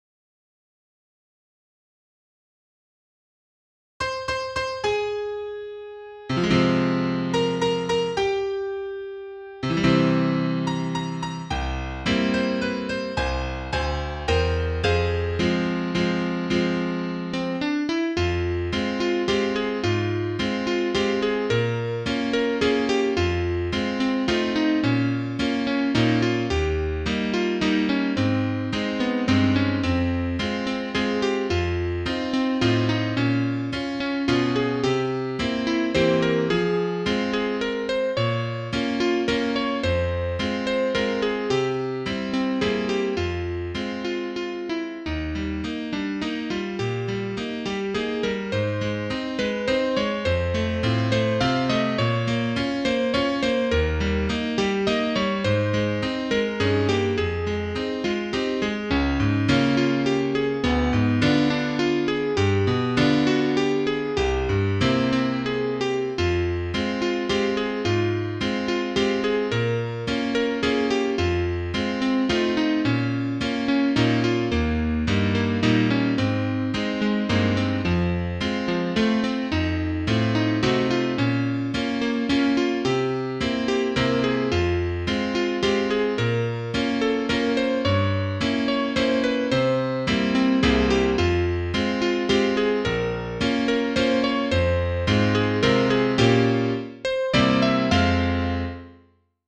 Trompete 2